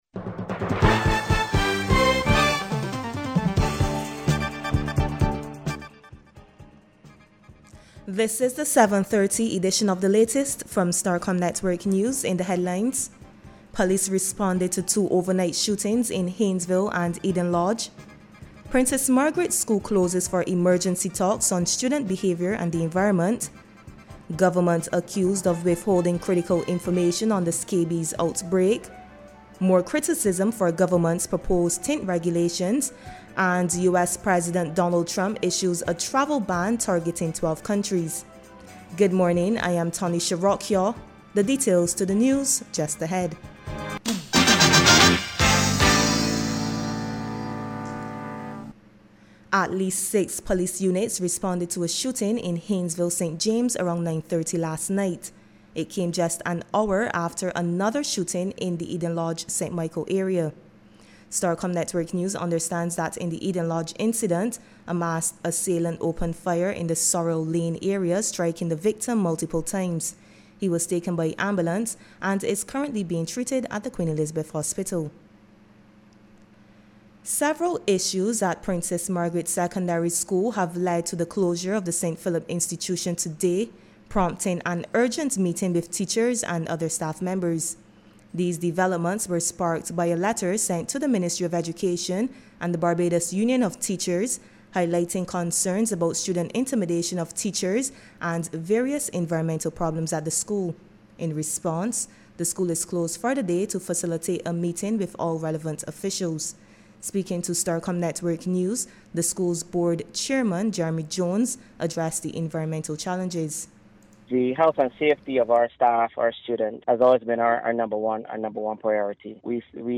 The Prime Minister was speaking during a high level Caribbean Financial Access Roundtable this morning at the Lloyd Erskine Sandiford Centre.